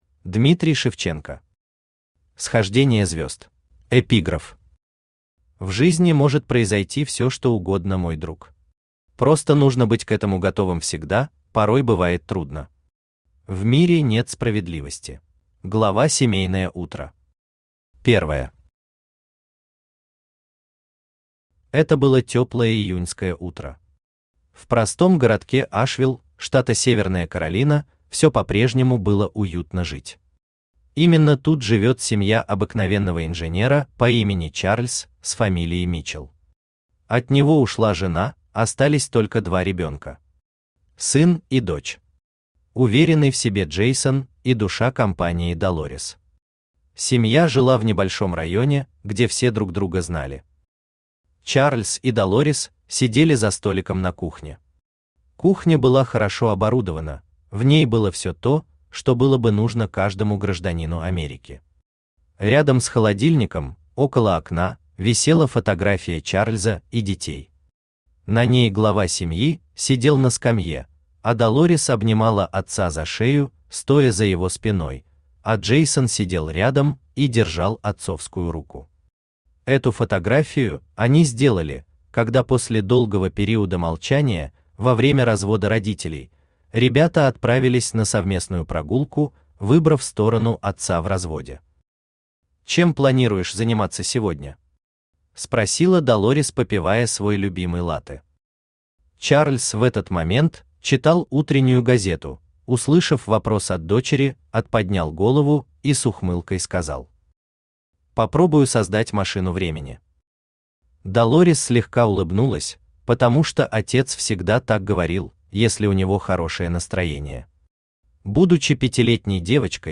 Аудиокнига Схождение звезд | Библиотека аудиокниг
Aудиокнига Схождение звезд Автор Дмитрий (WarnINg) Шевченко Читает аудиокнигу Авточтец ЛитРес.